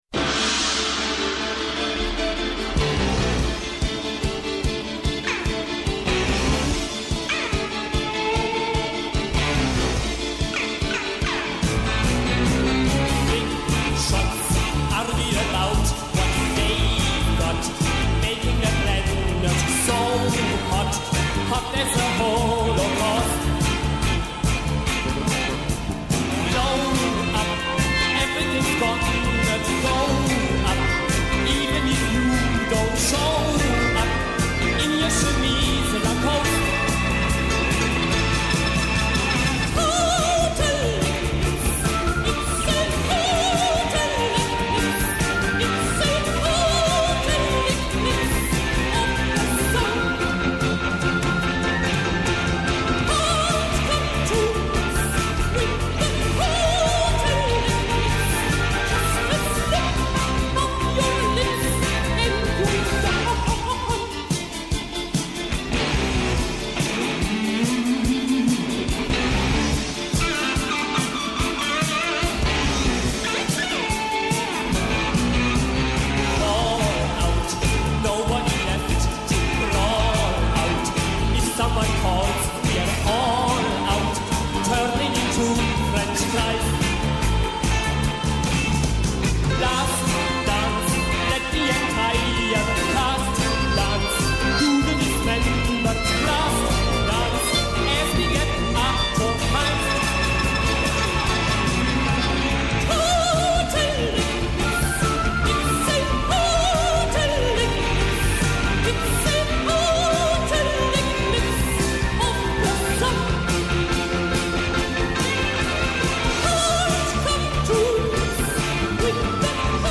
Mόνο στο Πρώτο Πρόγραμμα